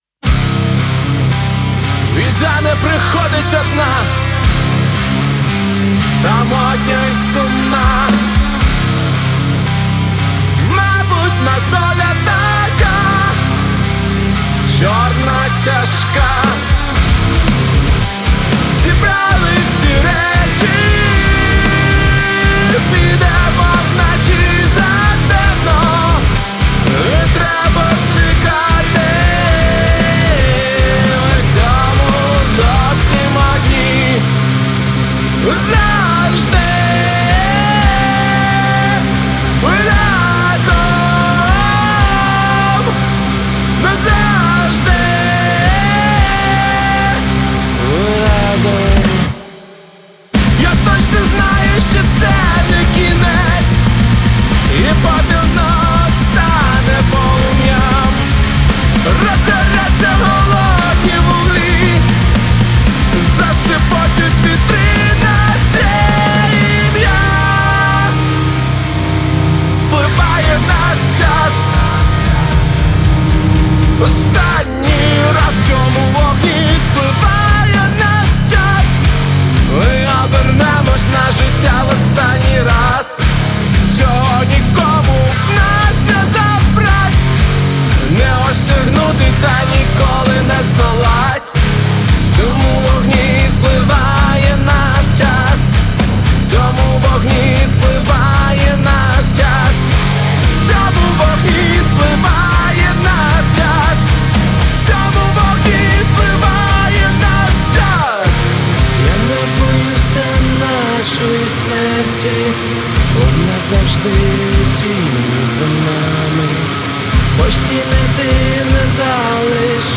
• Жанр: Rock, Metal